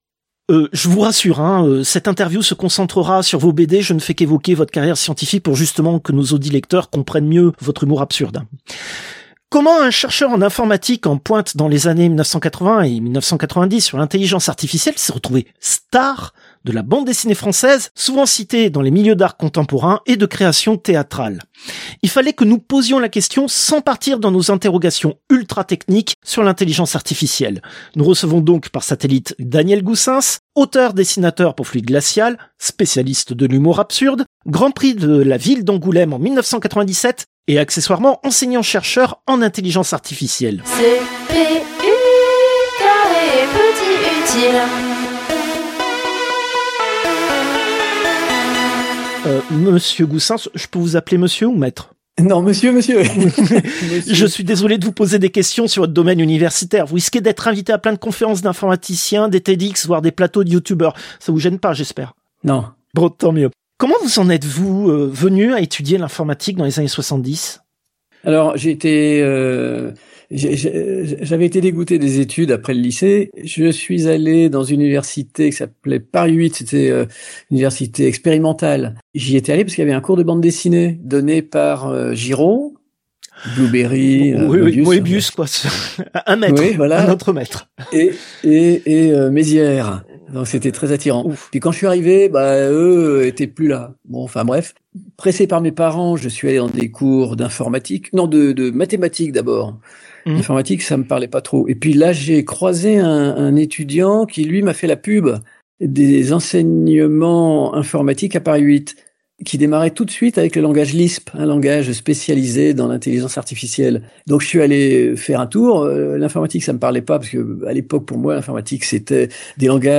Interview diffusée dans l'émission CPU release Ex0238 : Lisp et systèmes experts.